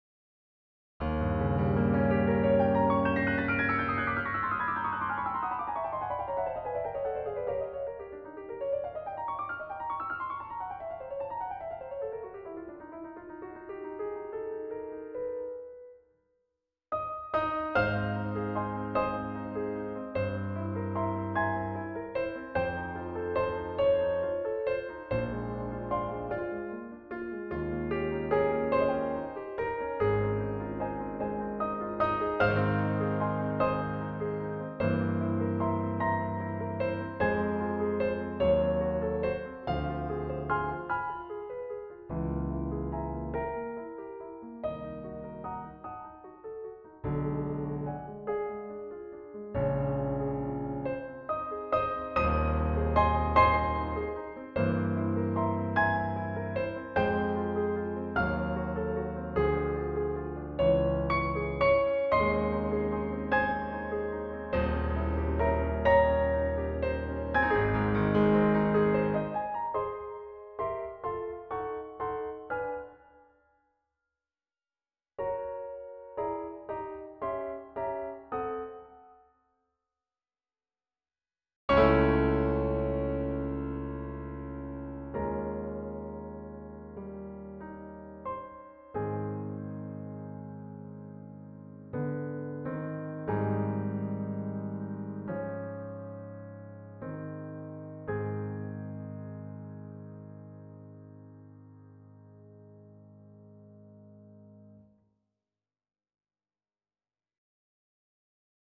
with a 19th century twist